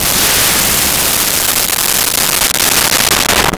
Train Engine Steam Blast
Train Engine Steam Blast.wav